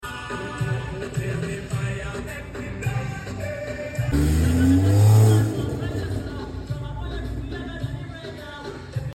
🔥or 🗑? valve diverter open sound effects free download
🔥or 🗑? valve diverter open sounds crazy ngl chat